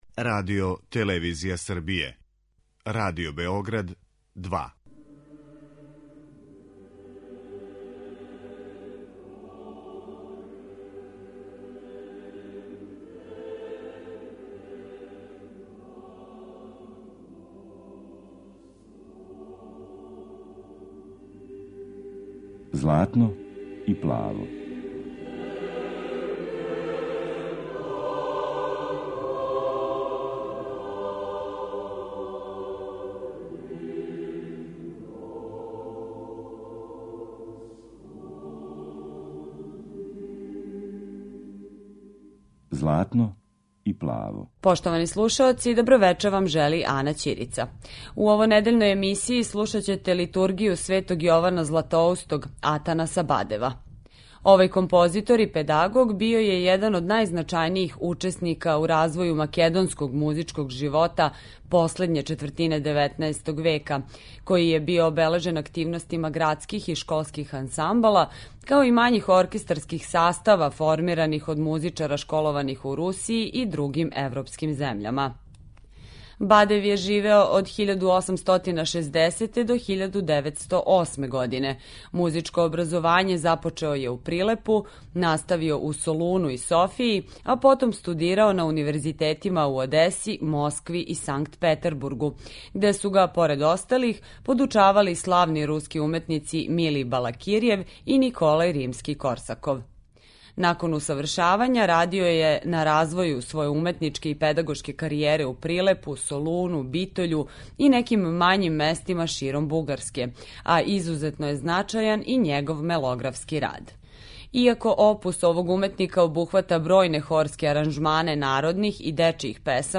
Емисија посвећена православној духовној музици.